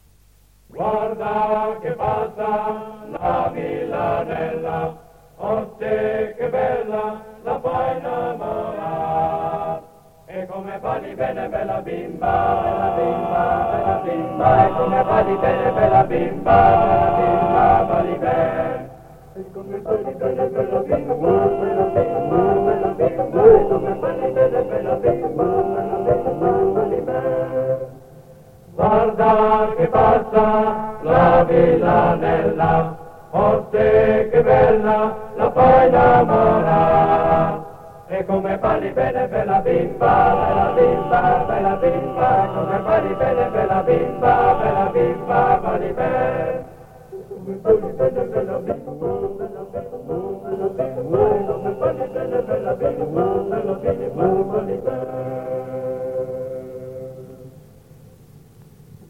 7 giugno 1978». 1975. 1 bobina di nastro magnetico.
A.7.1.16 - La villanella (Coro della SAT, disco Columbia 1933)